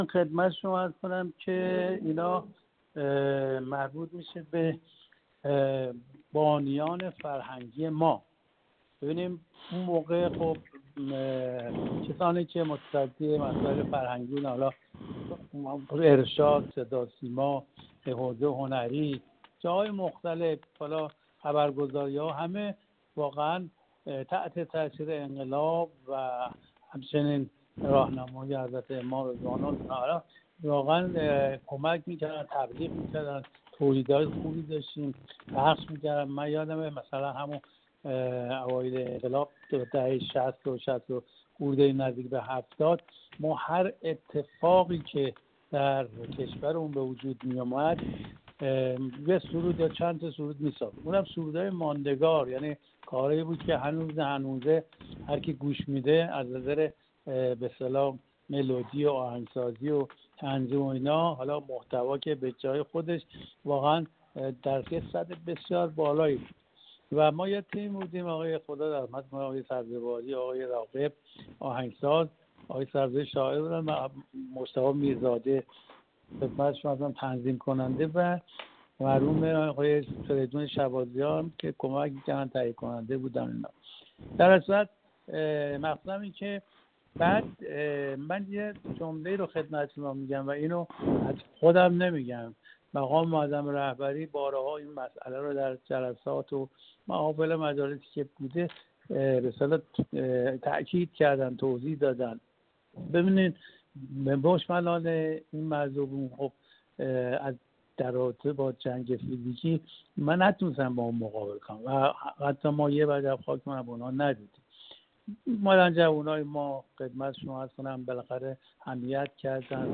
محمد گلریز پاسخ داد